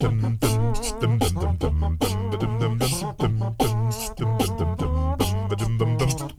ACCAPELLA10B.wav